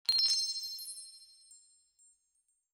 gems.wav